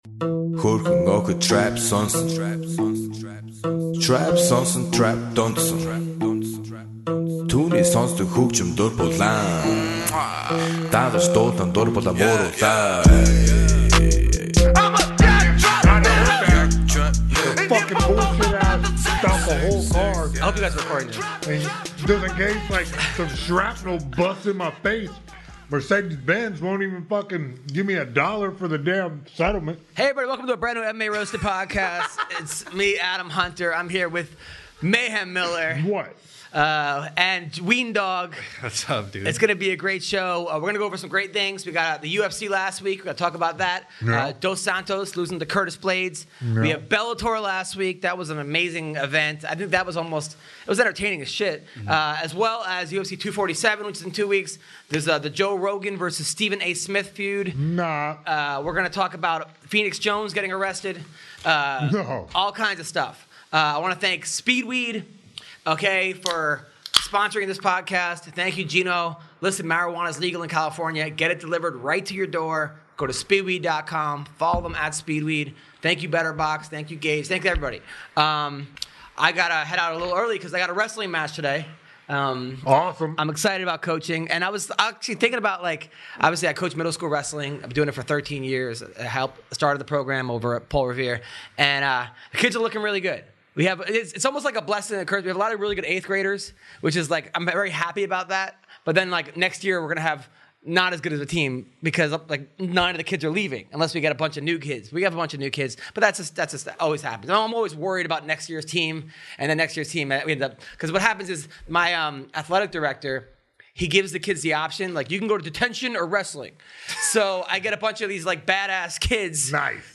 The crew takes a call